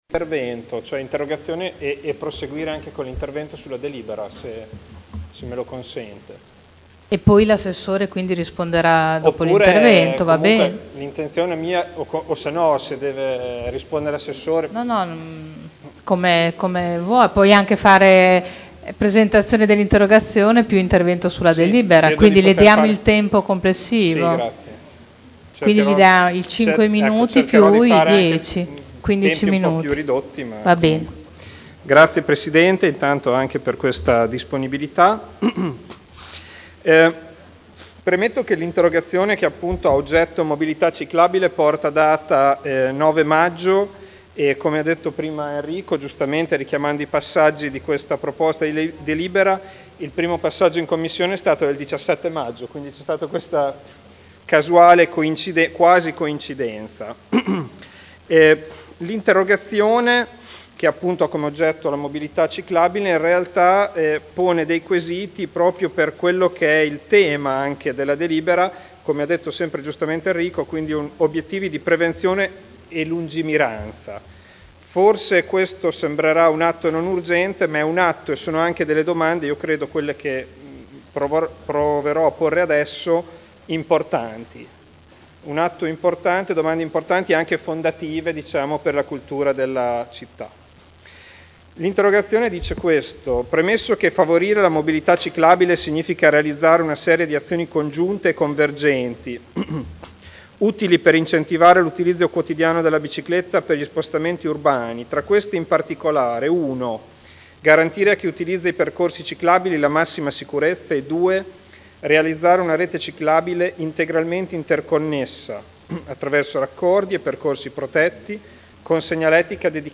Seduta del 21/07/2011. Interrogazione del consigliere Ricci (Sinistra per Modena) avente per oggetto: “Mobilità ciclabile” (presentata il 9 maggio 2011 - in trattazione il 21.7.2011) e intervento su Delibera: Mobilità ciclistica: formalizzazione, adeguamento e implementazione di un Piano generale della mobilità ciclistica (PGMC) – Proposta di deliberazione presentata dai consiglieri Artioli, Prampolini, Rossi F., Rocco, Garagnani, Pini, Dori, Glorioso, Gorrieri, Campioli, Goldoni, Trande, Caporioni (P.D.) (Commissione consiliare del 17 maggio 2011 e del 7 luglio 2011)